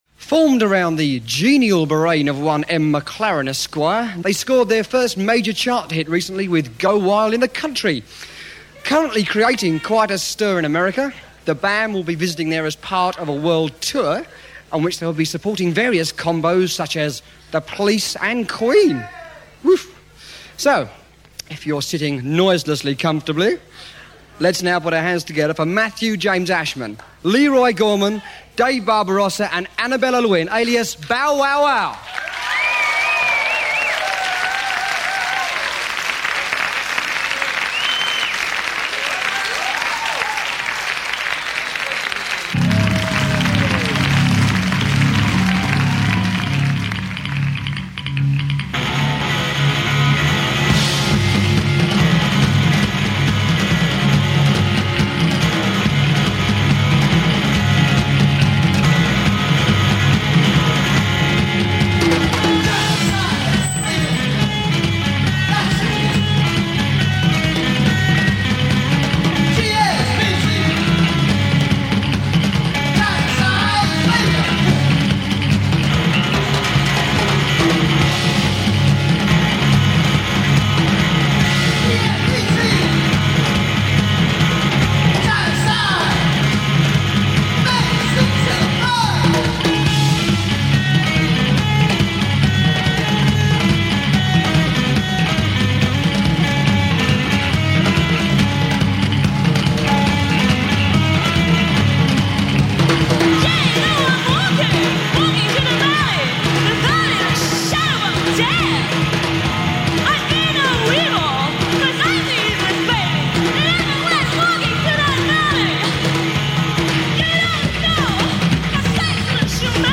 distinctive vocals
heavy emphasis on surf instrumentals and tribal beats